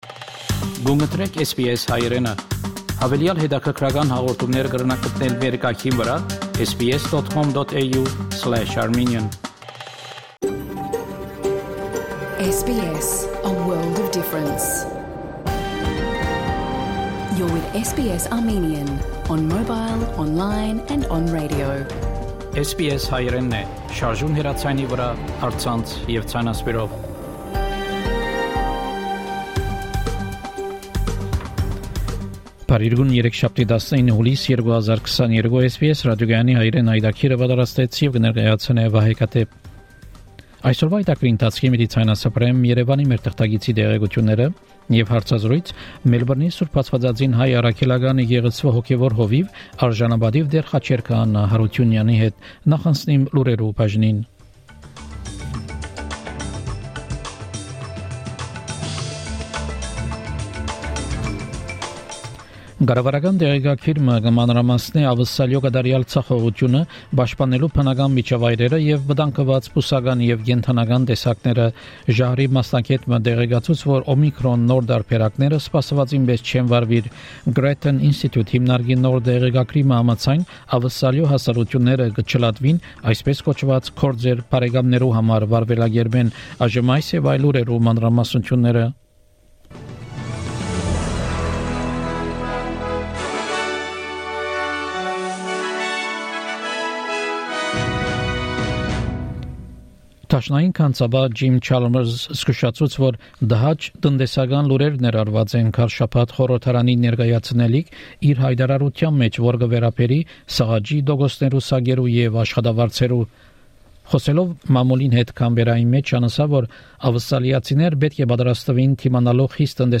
SBS Armenian news bulletin – 19 July 2022
SBS Armenian news bulletin from 19 July 2022 program.